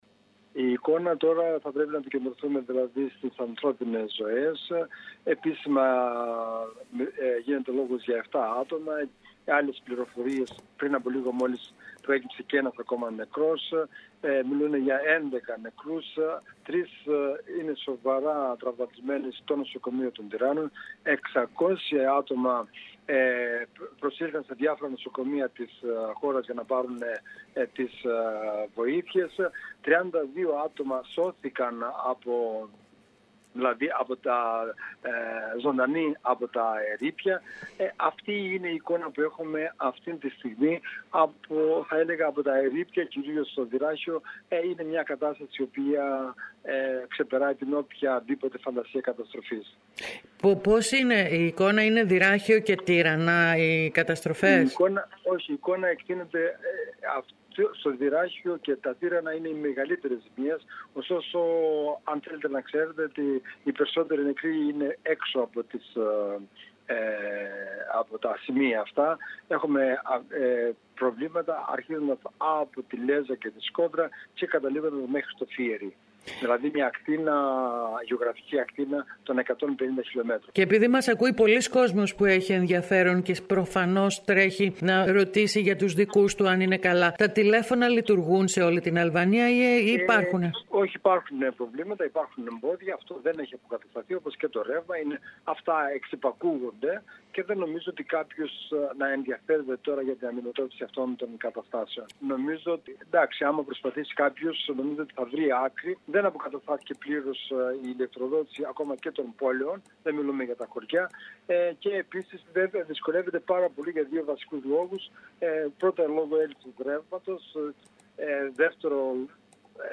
Κέρκυρα: Αισθητός στη ΒΔ Ελλάδα ο φονικός σεισμός του Δυρραχίου – Ανταπόκριση